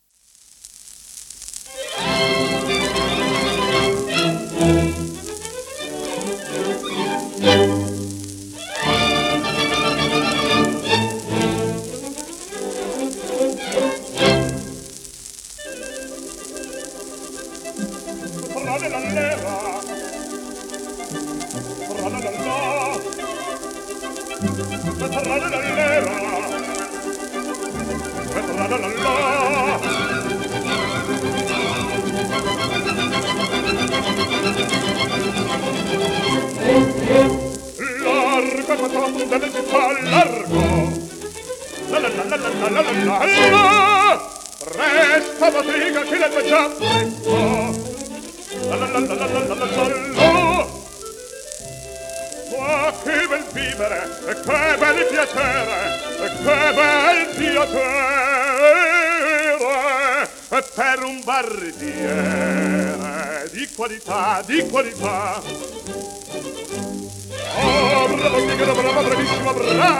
1928年録音
1920年代のイタリアで幅広く活躍したバリトン歌手